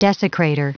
Prononciation du mot desecrator en anglais (fichier audio)
Prononciation du mot : desecrator